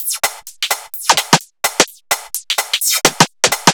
Index of /musicradar/uk-garage-samples/128bpm Lines n Loops/Beats
GA_BeatFiltC128-07.wav